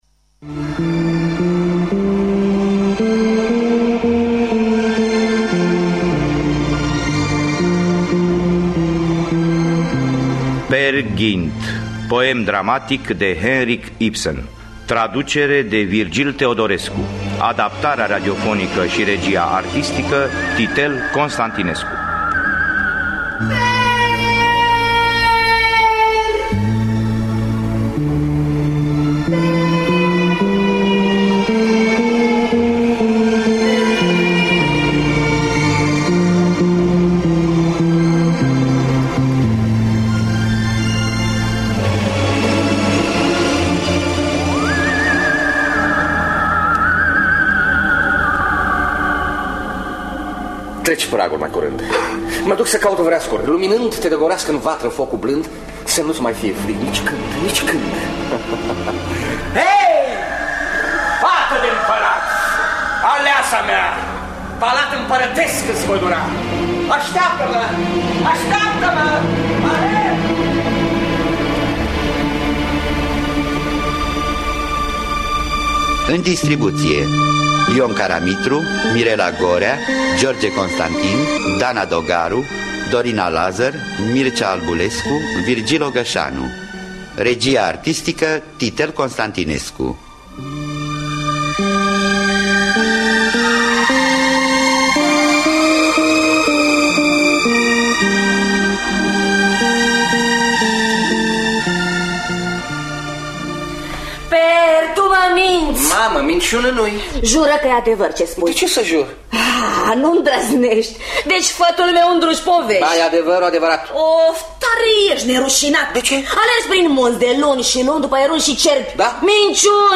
„Peer Gynt”, poem dramatic de Henrik Ibsen